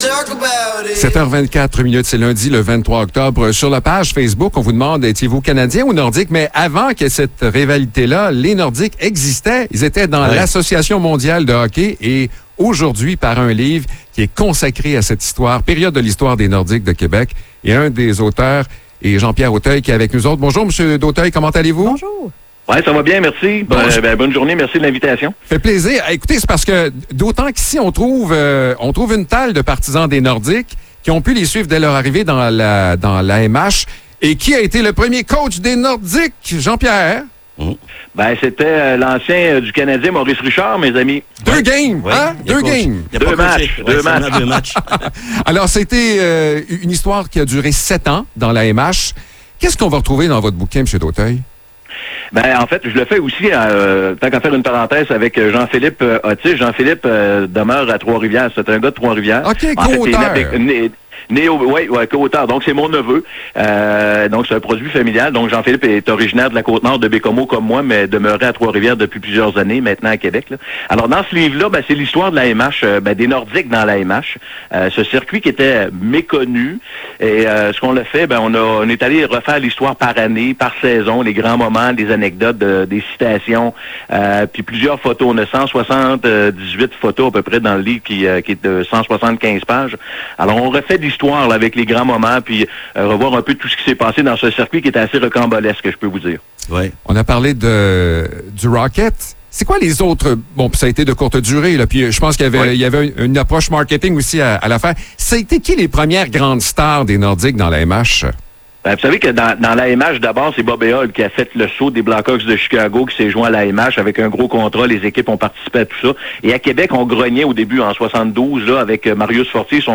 Entrevue sur le livre L’épopée des Nordiques de Québec dans l’AMH